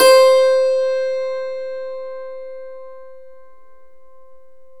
Index of /90_sSampleCDs/E-MU Formula 4000 Series Vol. 4 – Earth Tones/Default Folder/Hammer Dulcimer
DLCMR C3-L.wav